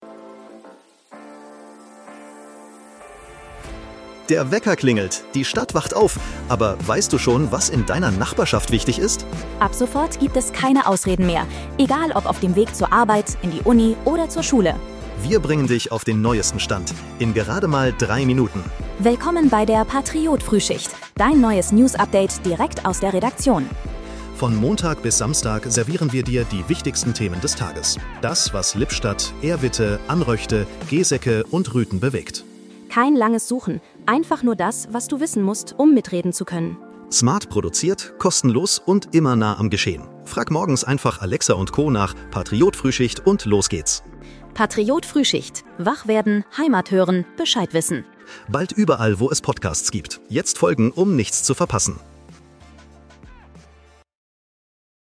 Willkommen zur Patriot-Frühschicht. Dein morgendliches News-Update
und mit Hilfe von Künstlicher Intelligenz.